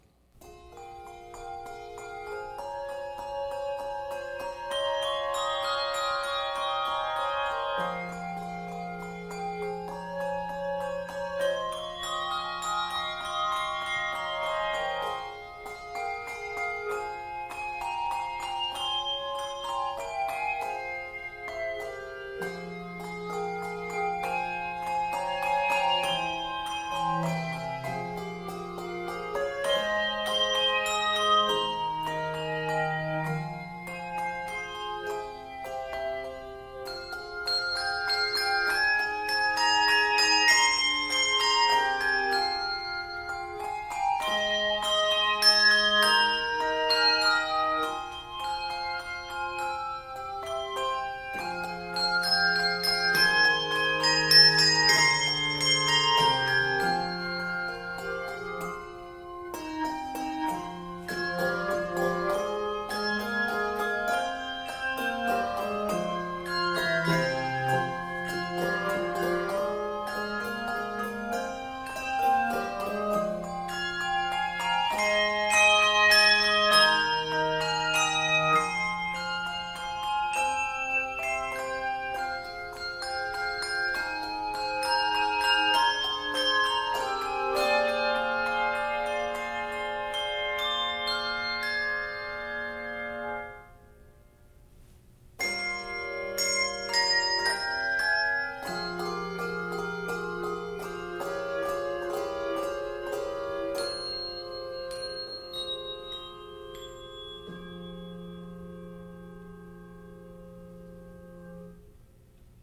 Solemn, sustained lines highlight this arrangement